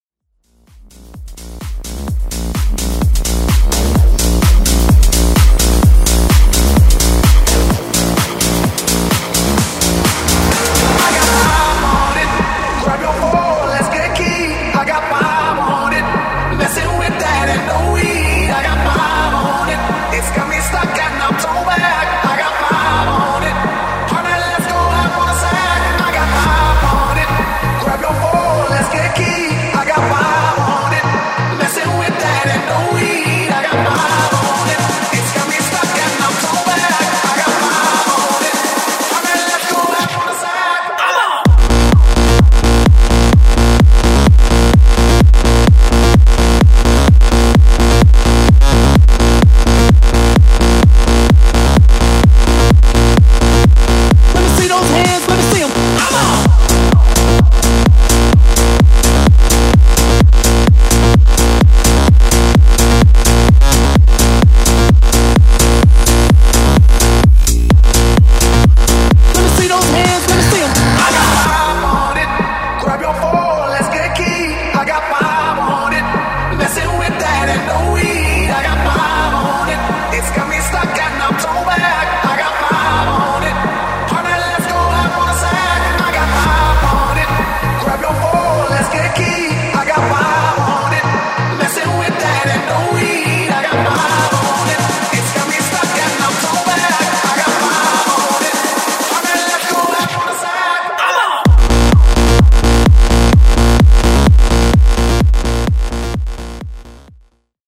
Genre: HIPHOP
Clean BPM: 98 Time